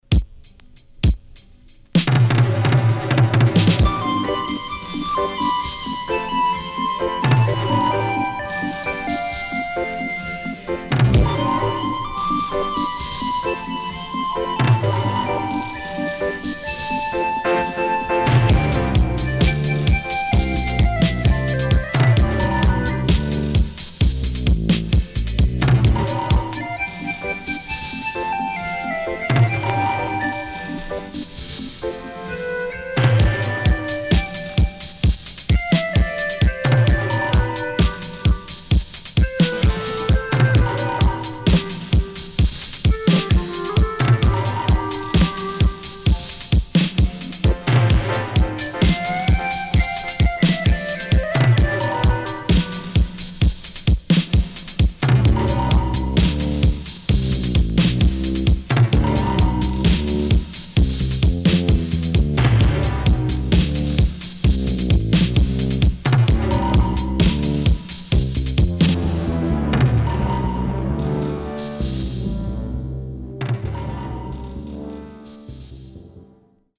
【12inch】
SIDE A:少しノイズ入ります。